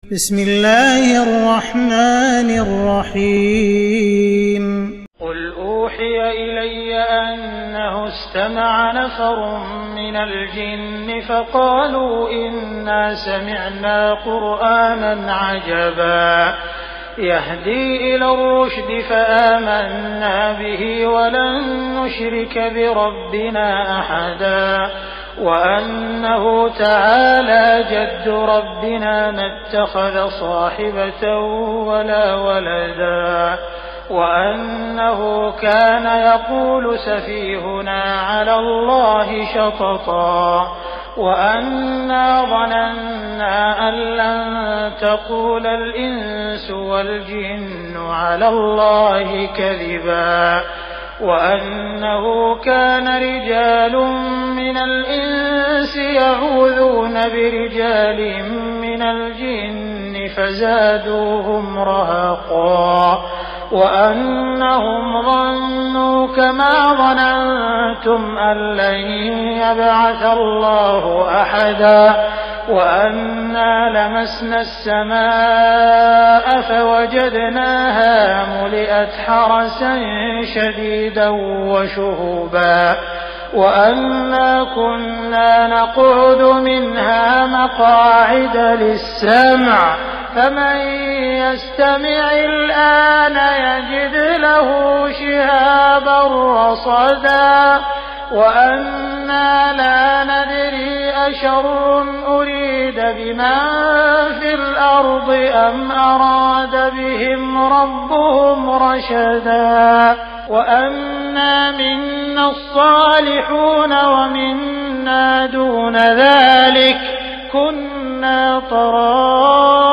تراويح ليلة 28 رمضان 1418هـ من سورة الجن الى المرسلات Taraweeh 28 st night Ramadan 1418H from Surah Al-Jinn to Al-Mursalaat > تراويح الحرم المكي عام 1418 🕋 > التراويح - تلاوات الحرمين